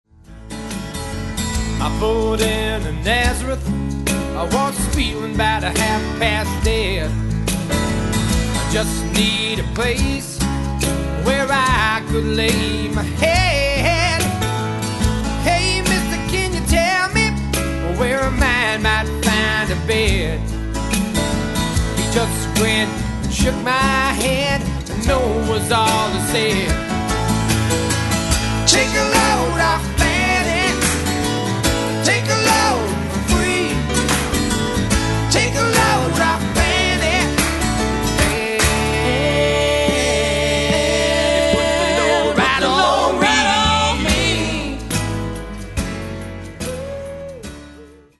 intimate and acoustic